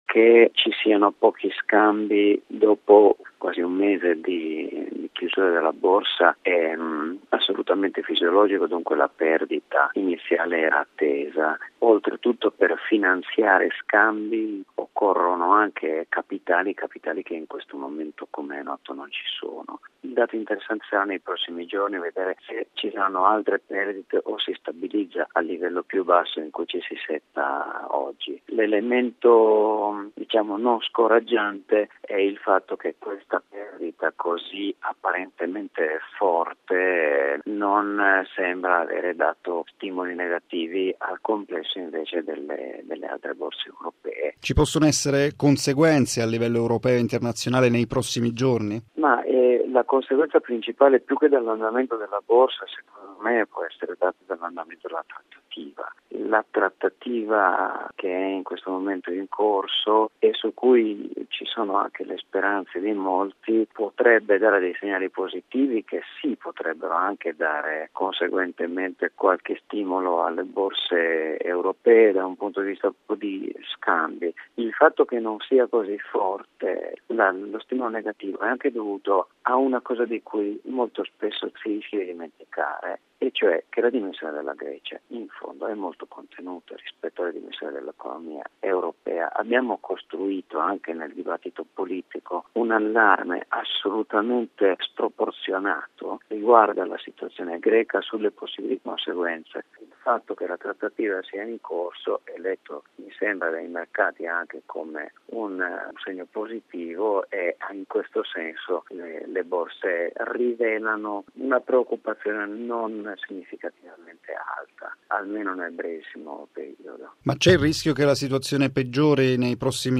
ha intervistato l’economista